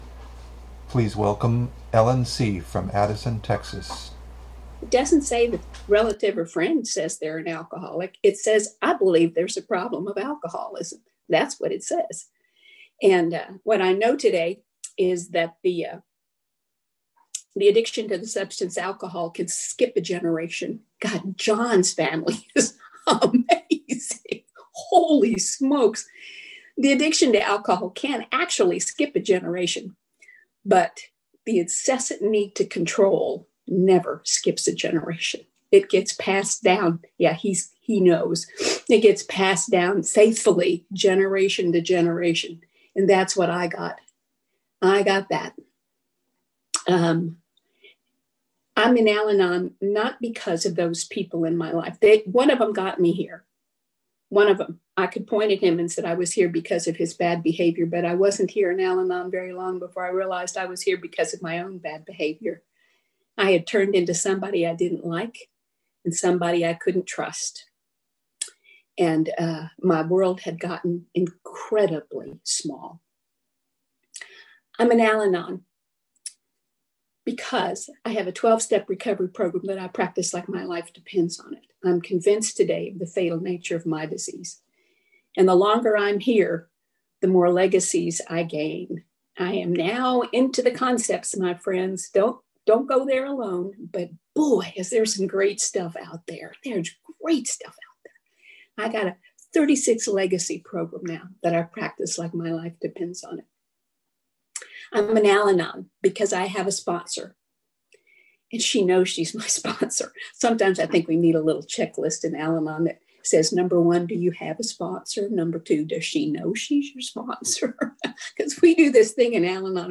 46th San Fernando Valley Alcoholics Anonymous UnConventional